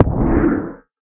elder_hit3.ogg